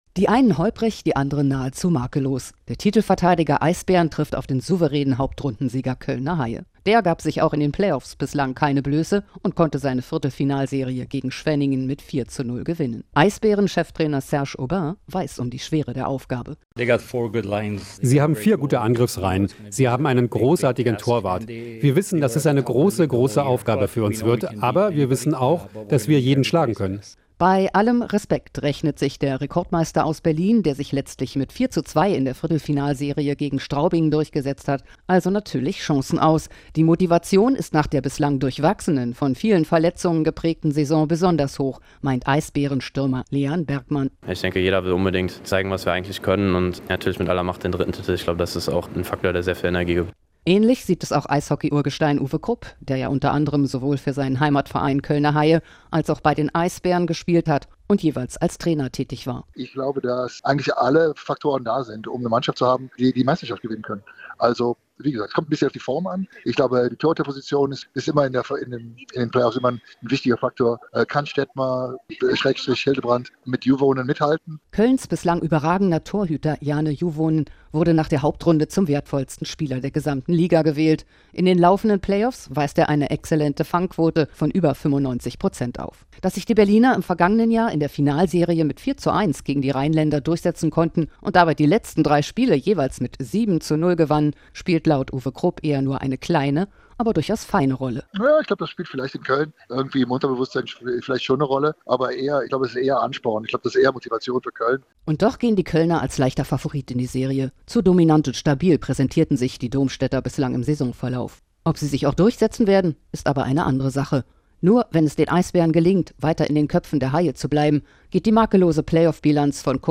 In Interviews und Reportagen blicken wir auf den Sport in der Region und in der Welt.